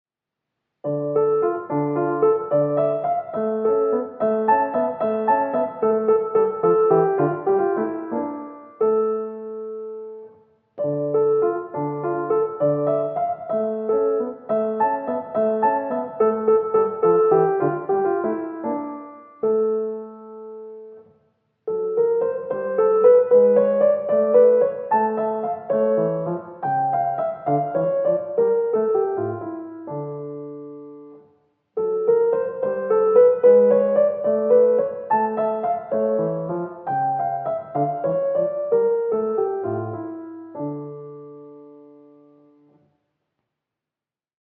mp3Seixas, Carlos de, Sonata No. 29 in D minor, mvt.
Minuet